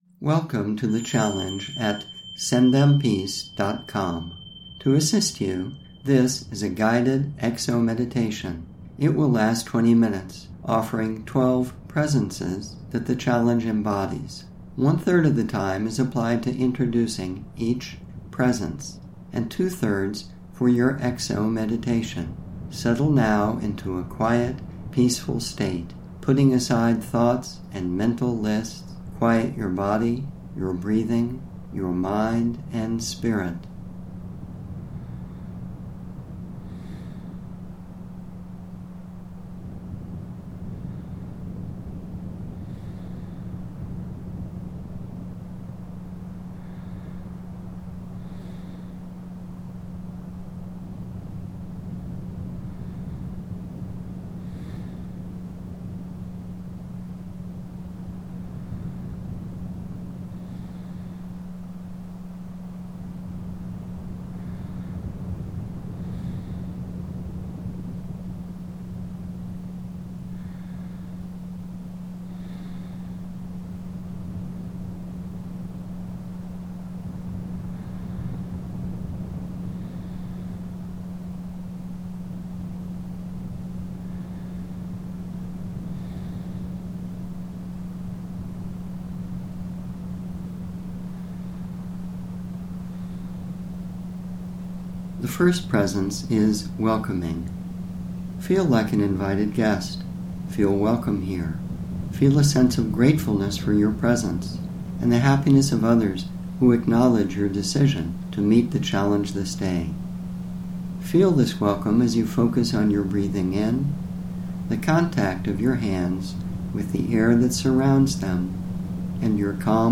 These place the simple recordings in an environment of alpha and theta brainwave frequencies to assist in quieting the mind and reaching present awareness more efficiently.
Herein, gamma waves might be found in a range from 40 to 77 Hertz (Hz), in these GEMs with BWE, most are centered around 44 Hz.
Guided-Exo-Meditation-with-BWE-Gamma--Level-3-.mp3